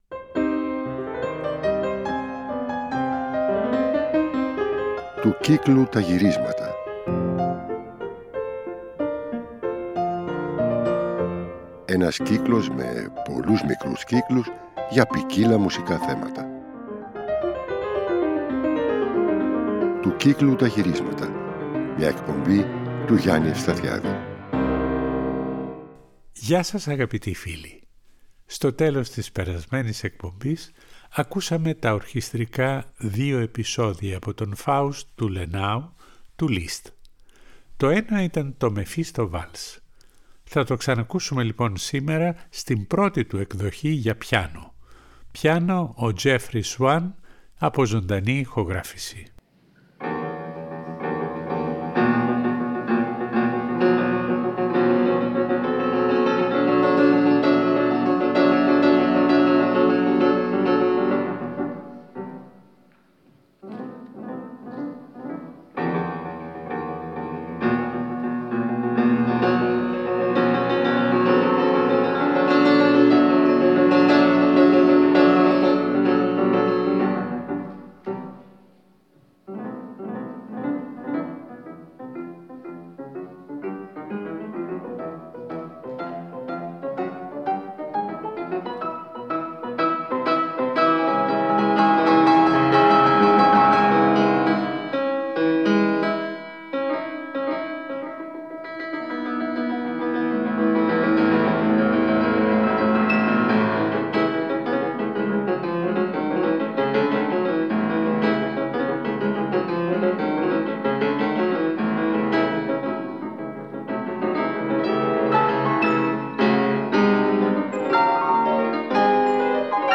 Πρώτα θα ακουστεί το «Μεφίστο βαλς» του Λίστ στην πιανιστική του όμως πρώτη εκδοχή.